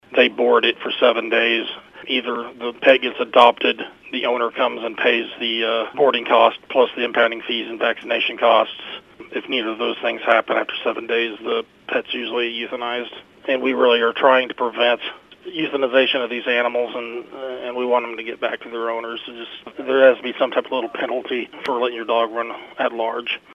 City Administrator Jamie Lawrence says there are some changes to the new ordinance, and they have gotten feedback from other towns. He explains what those changes are.